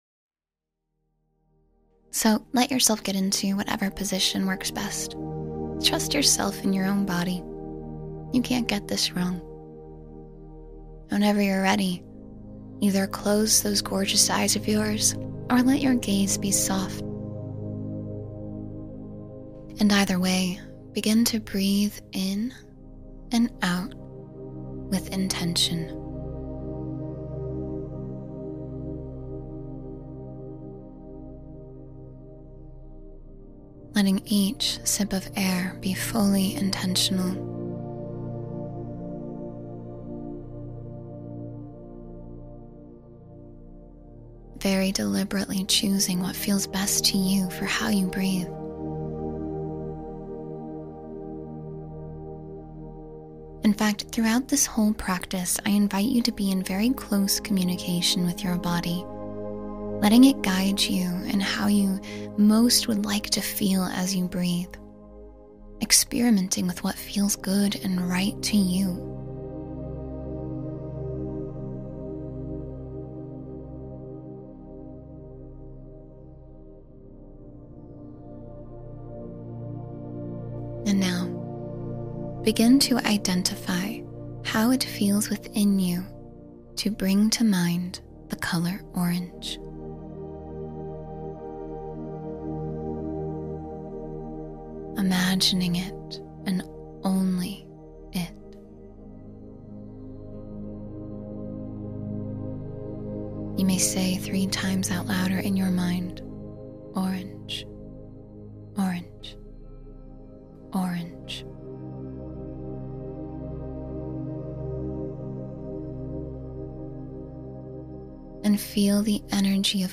Feel the Energy Flow Through You — Meditation for Vitality and Renewal